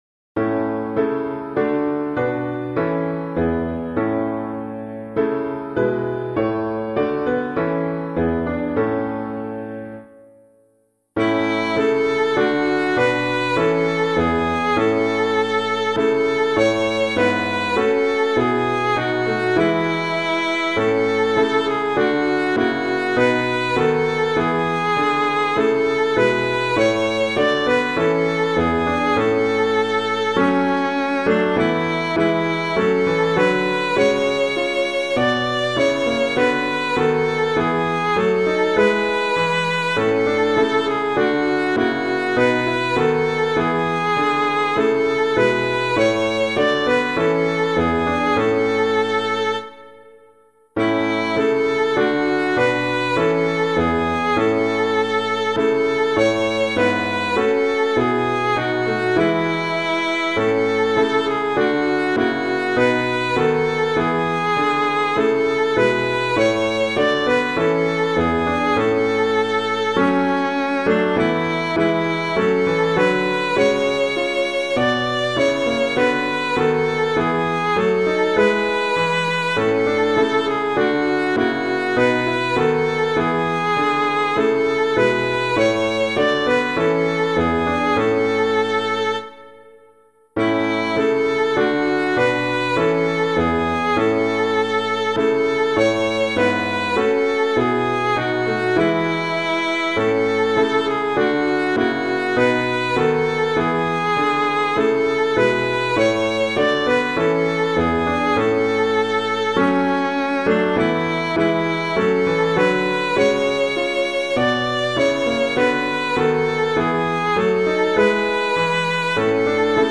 piano
Christ the Lord Is Risen Today [Leeson - VICTIMAE PASCHALI] - piano.mp3